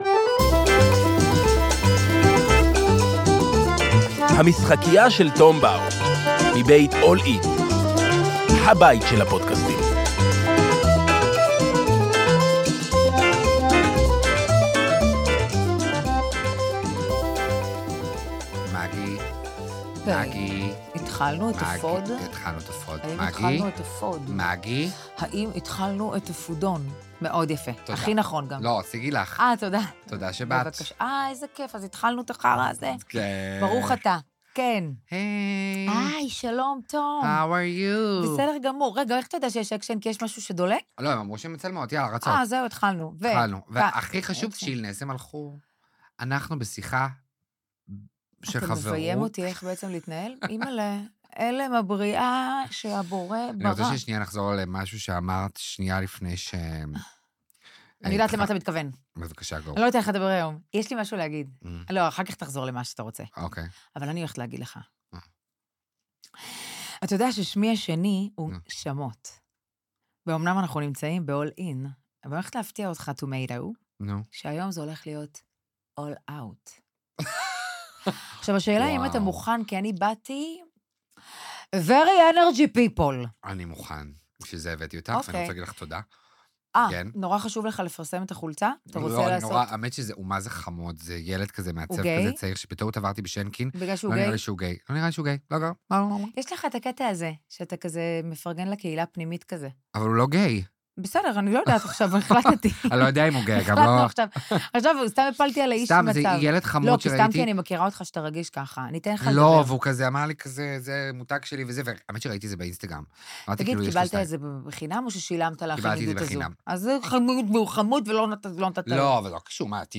טום ואוריאל יושבים למשחק ארבע בשורה עם שאלות ומשימות שמובילות לשיחה מרתקת שלא רצינו לחתוך באמצע.